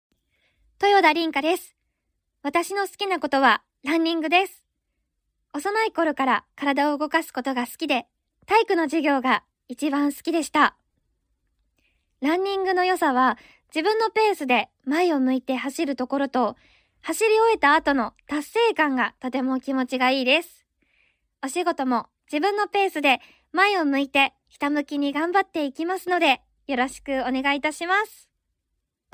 ボイスサンプル
自己紹介